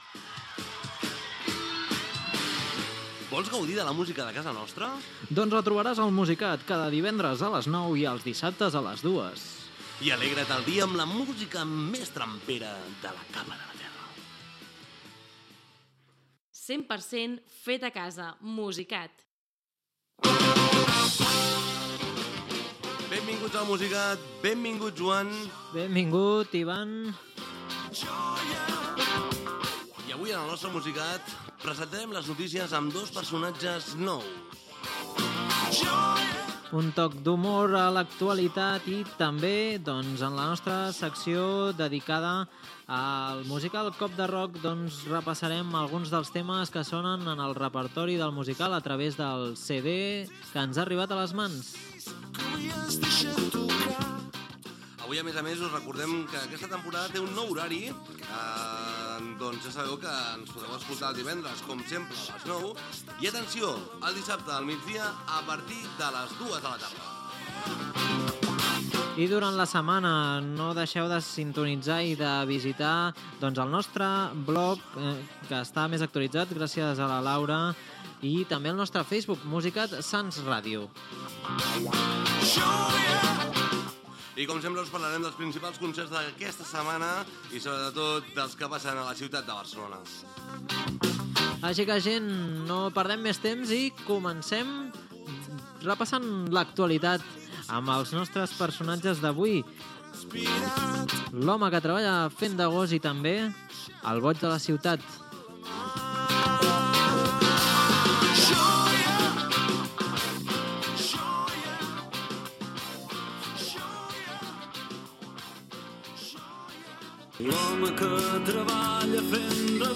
Careta del programa, sumari, tema musical i indicatiu
FM